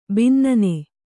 ♪ binnane